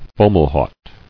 [Fo·mal·haut]